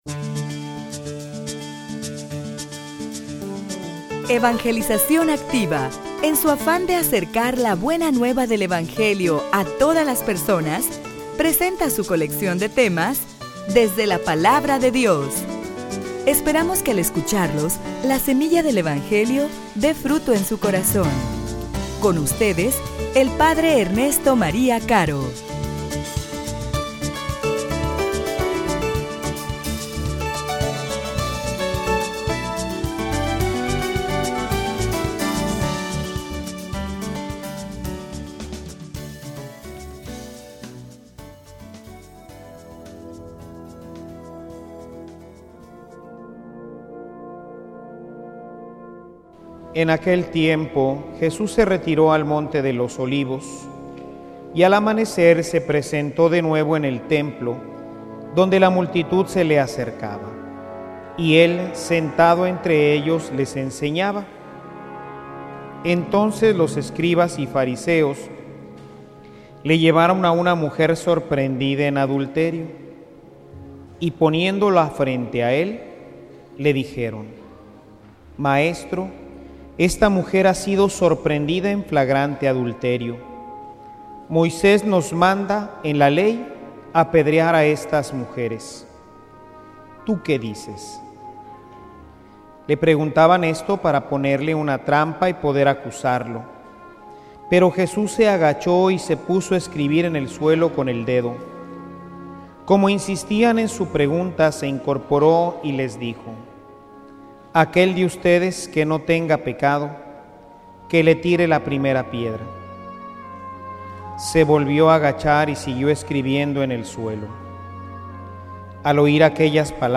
homilia_Yo_tampoco_te_condeno.mp3